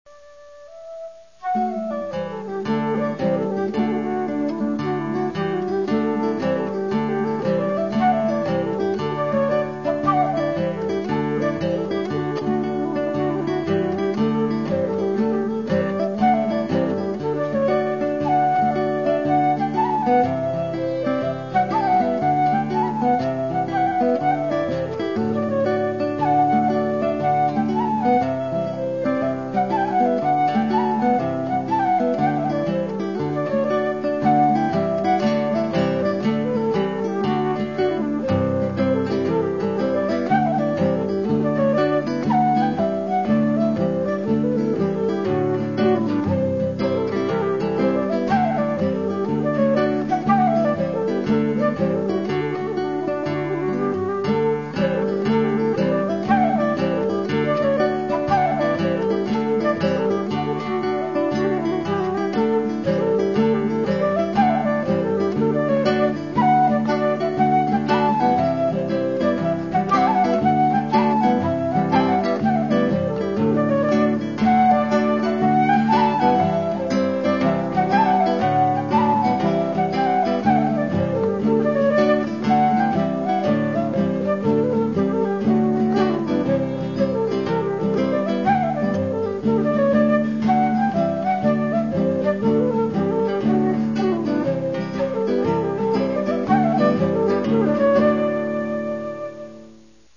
(A classic Irish jig, guaranteed to banish the blues).